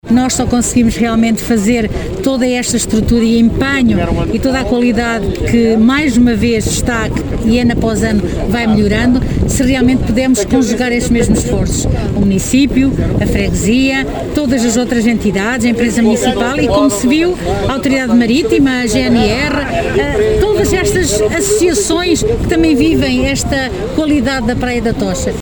Foi içada hoje, pela 31ª vez, a Bandeira Azul na praia da Tocha, em Cantanhede, numa cerimónia que decorreu no Centro de Interpretação da Arte Xávega.
À margem deste evento, Helena Teodósio falou da conjugação de esforços para que acções como esta aconteçam: